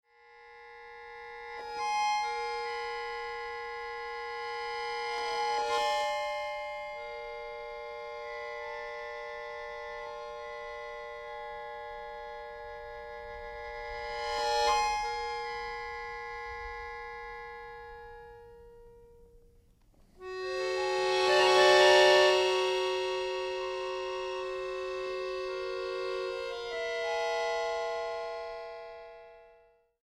acordeonista
actrice y cantante